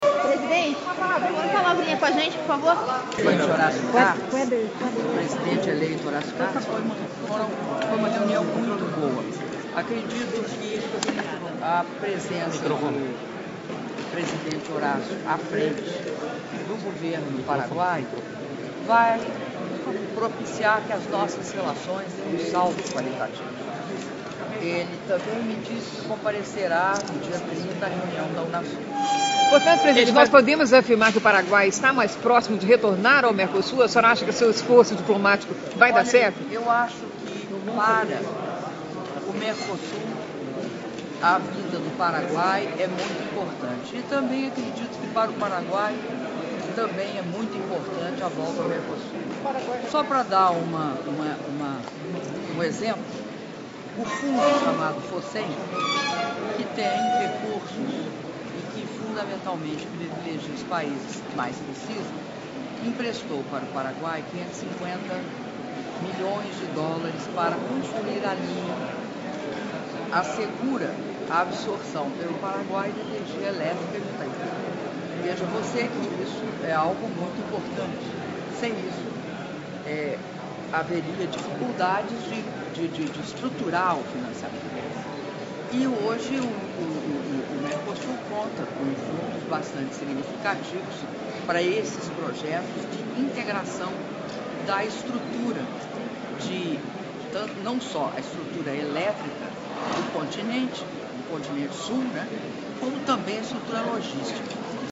Entrevista coletiva concedida pela Presidenta da República, Dilma Rousseff, no Hotel Bourbon, em Assunção - Paraguai